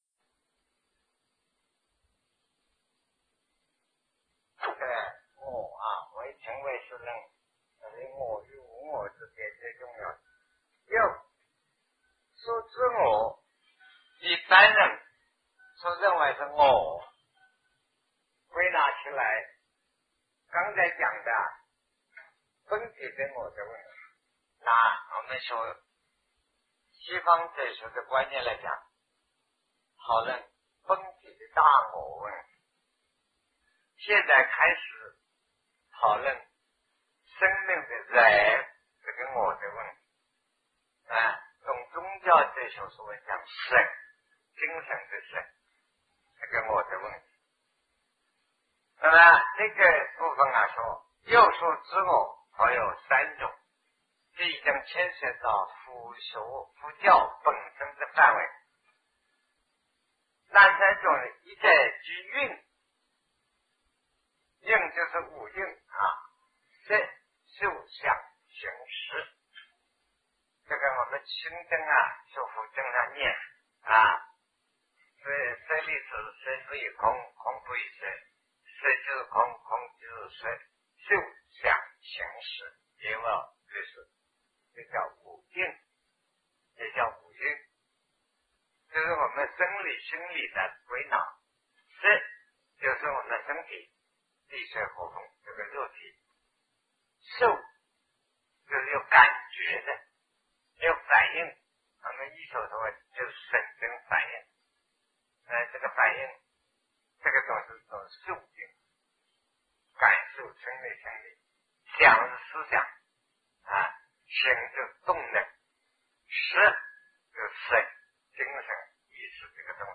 生命执我略有三种 南师讲唯识与中观（1981于台湾052(上)